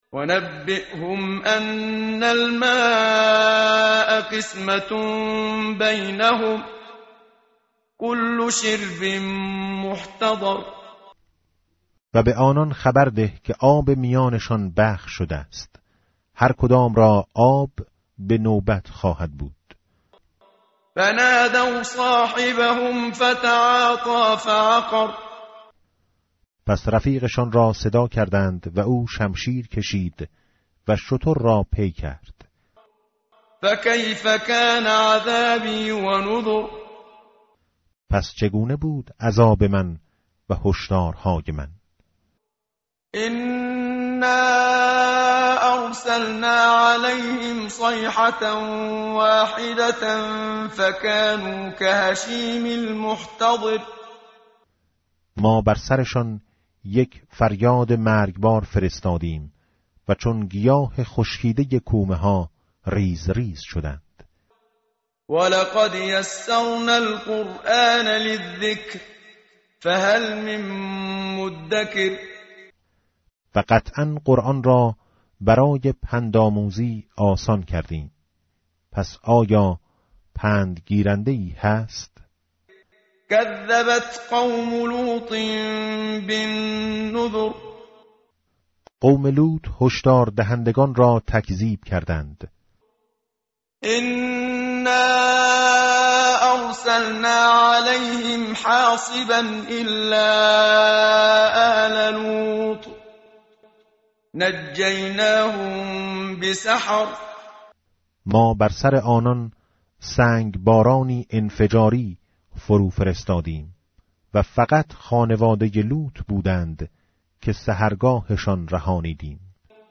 متن قرآن همراه باتلاوت قرآن و ترجمه
tartil_menshavi va tarjome_Page_530.mp3